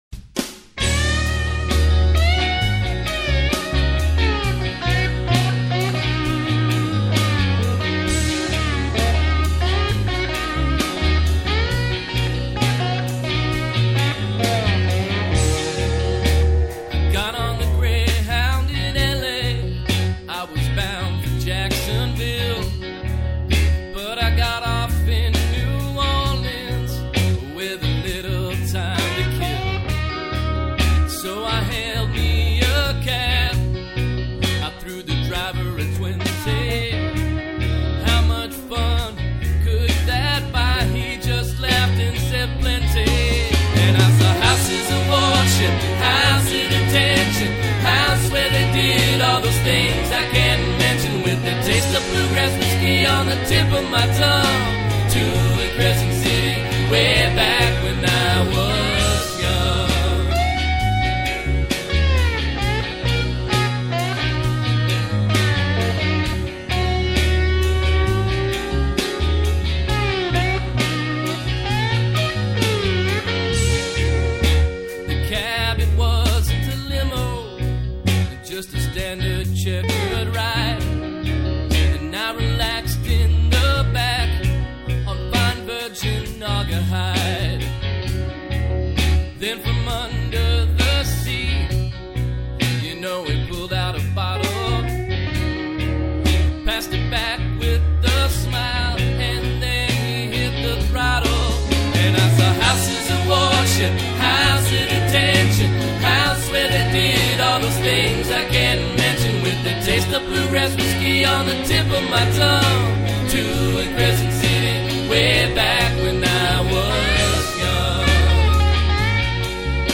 Texas-Colorado-Ohio bluesman